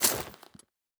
Foley Armour 03.wav